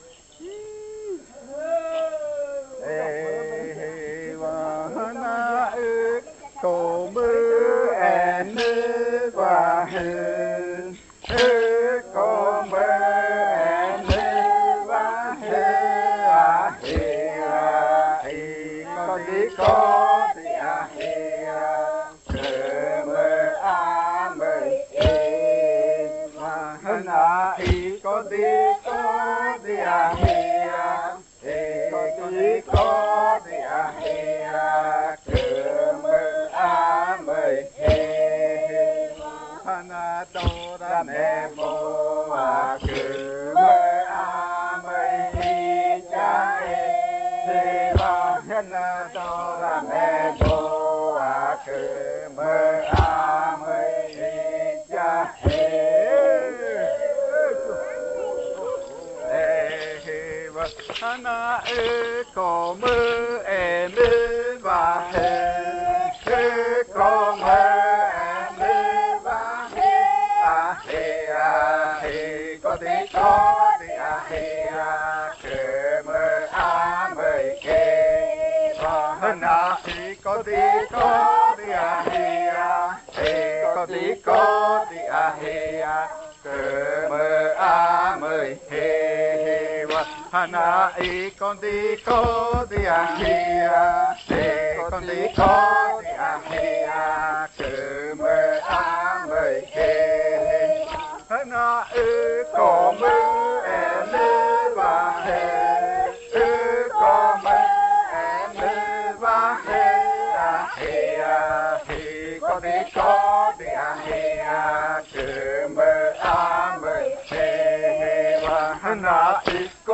Puerto Remanso del Tigre, departamento de Amazonas, Colombia
Séptimo canto del baile de Pichojpa Majtsi
en casete